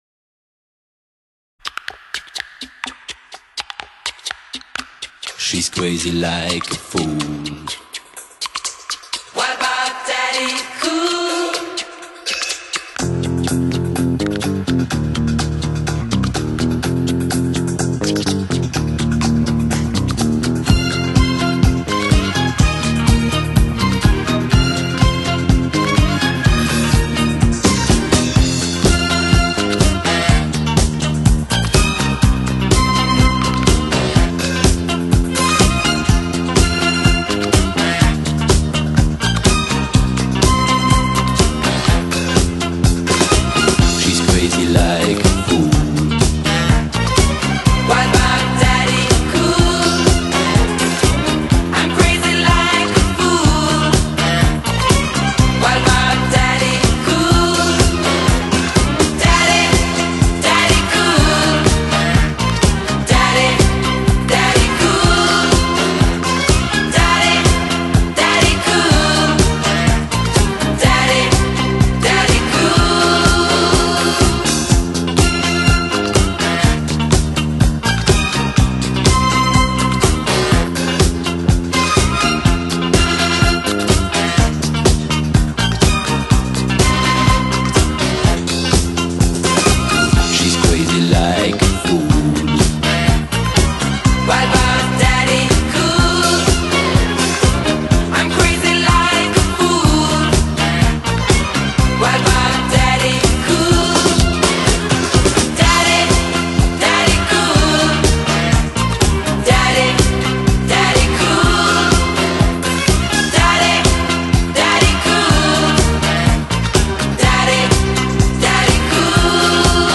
Type: The Remastered Editions